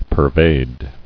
[per·vade]